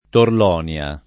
[ torl 0 n L a ]